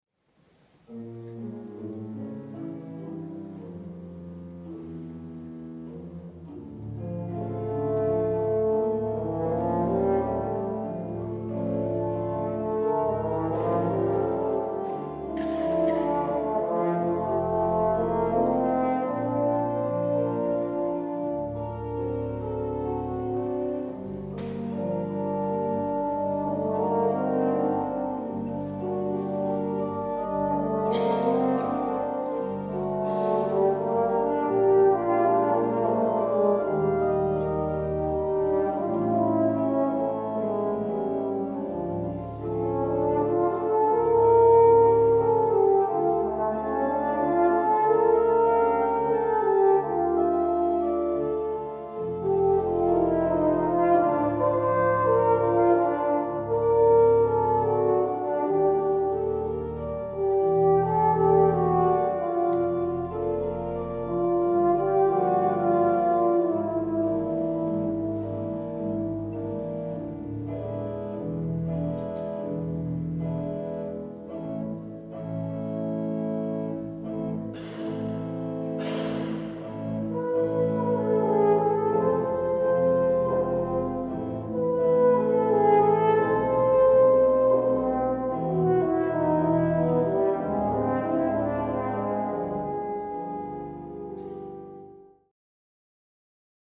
Vielseitiger Horn- und Orgelsound
Zum Abschluss des Konzertes erklangen mit Werken von Gliere und Saint Saëns, wiederum von Horn und Orgel gemeinsam vorgetragen, noch einmal klassische Klänge in der Arther Kirche.
«Valse triste» aus vier Stücke für Horn und Klavier op.35
Orgel